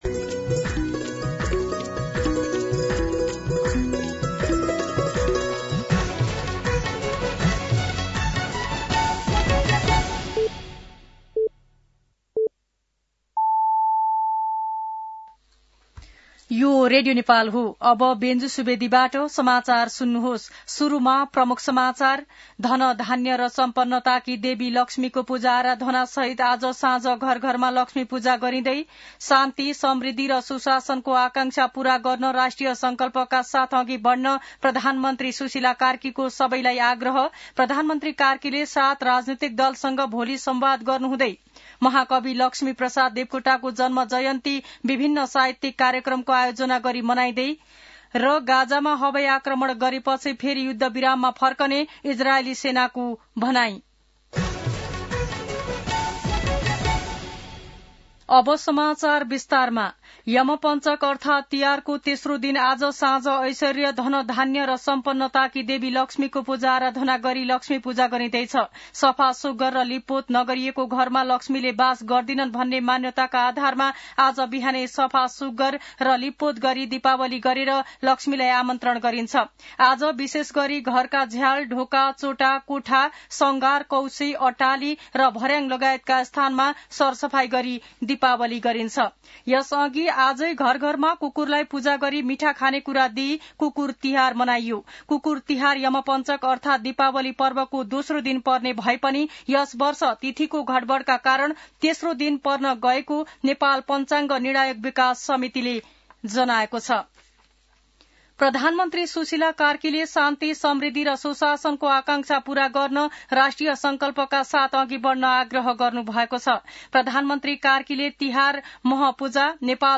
दिउँसो ३ बजेको नेपाली समाचार : ३ कार्तिक , २०८२
3-pm-Nepalii-News.mp3